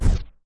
etfx_shoot_mystic.wav